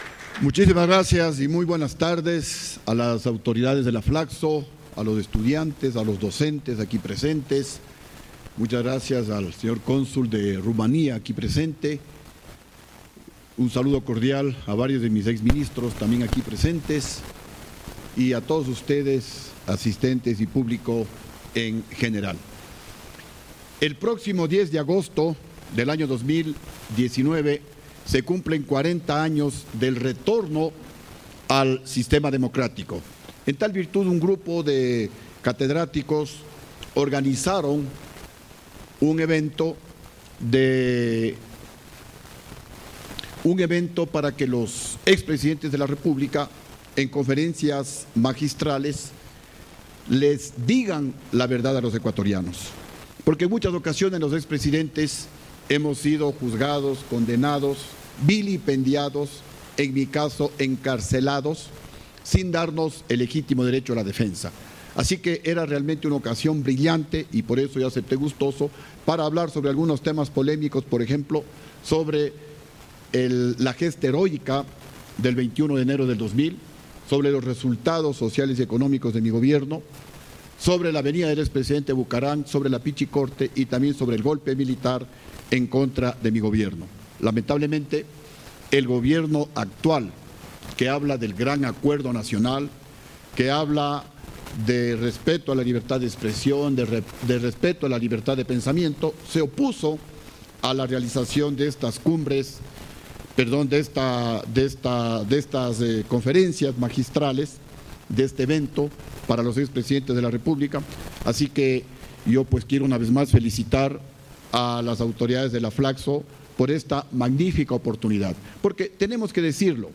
Foro
Con la participación de: Lucio Gutiérrez, presidente de la República de Ecuador 2003-2005.